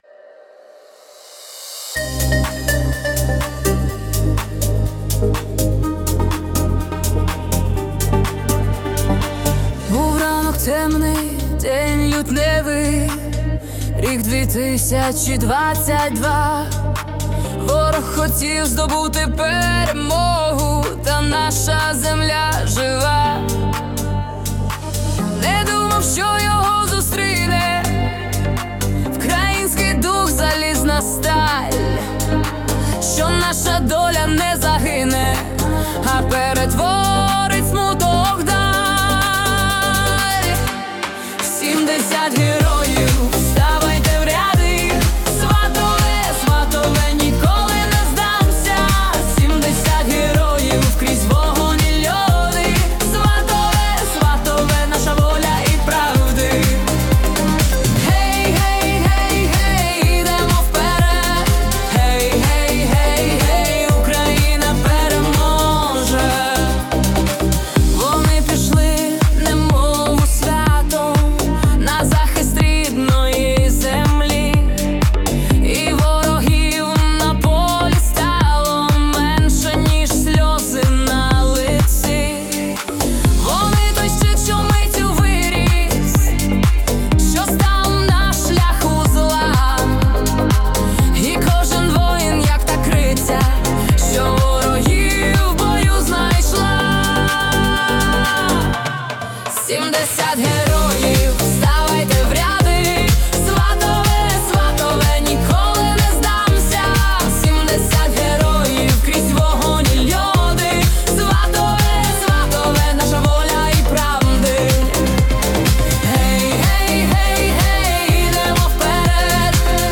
House Music (Energetic)